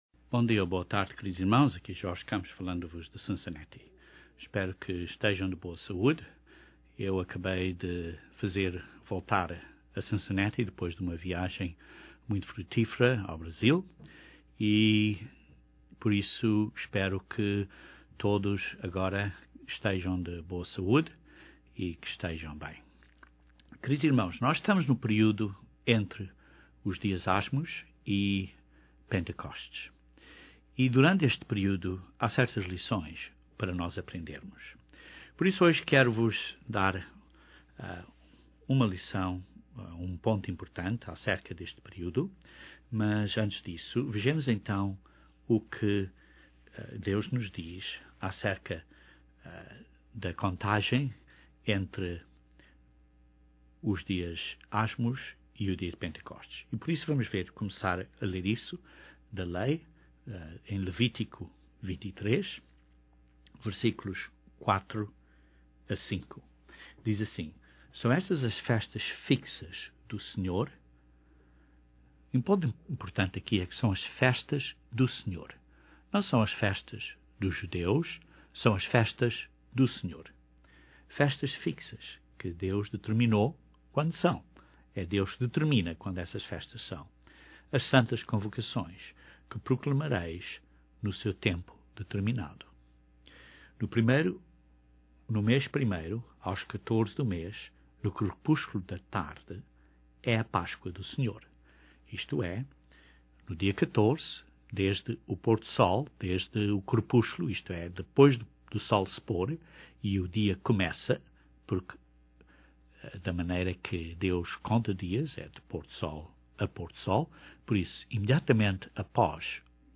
O periodo dos Asmos a Pentecostes tem um simbolismo de preparação para a habitação do Espírito Santo nos Cristãos. Este sermão explica este tema.